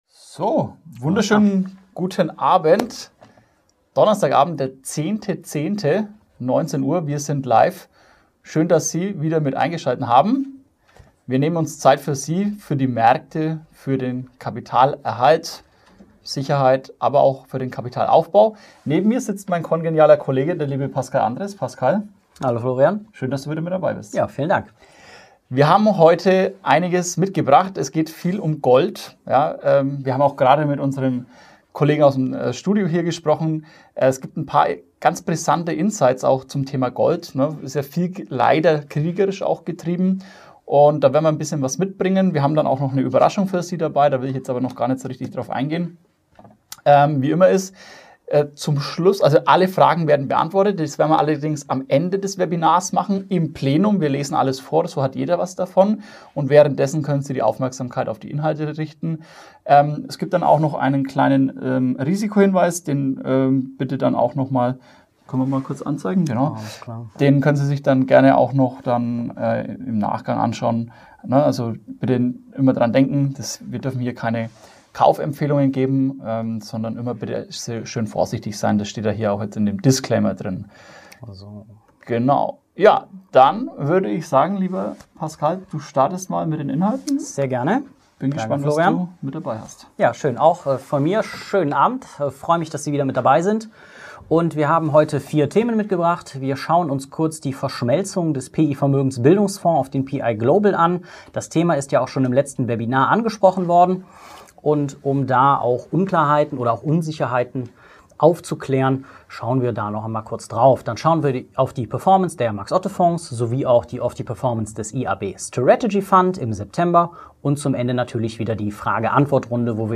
Monatsupdate